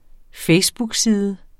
Udtale [ ˈfεjsbug- ]